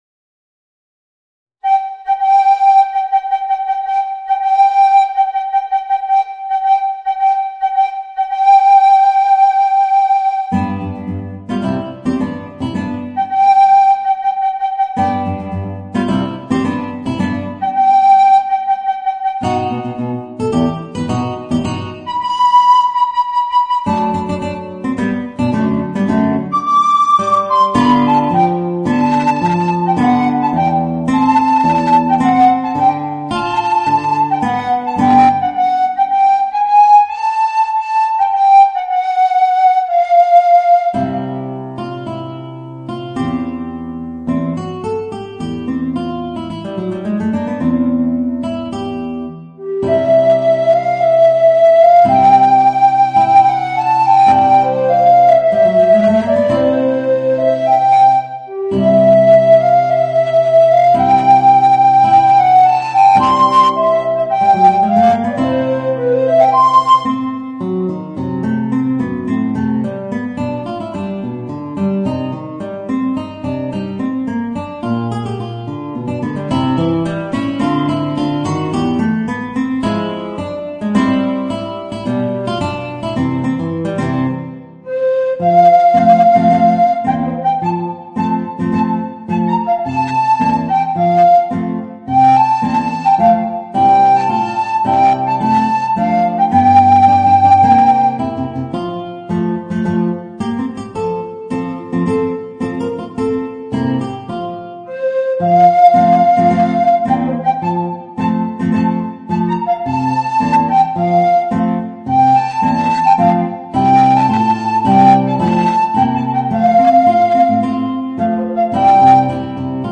Voicing: Guitar and Alto Recorder